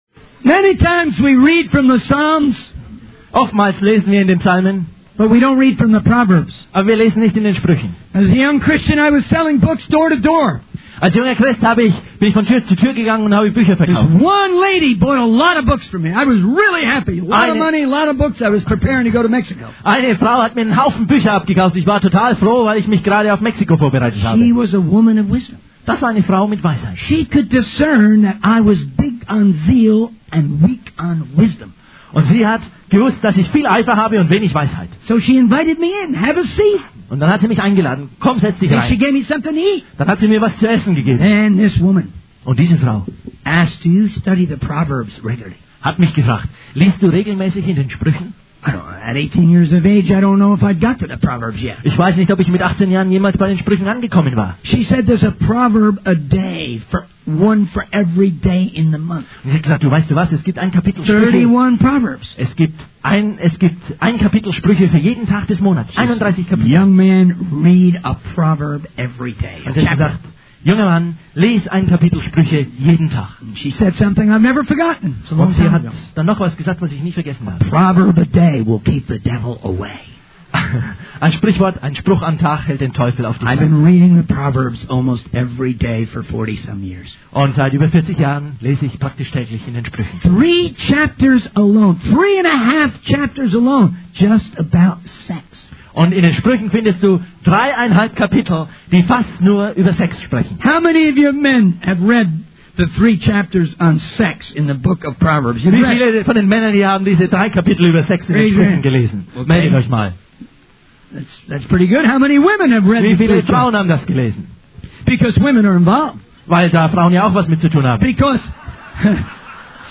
In this sermon, the speaker shares two stories from their ministry experiences.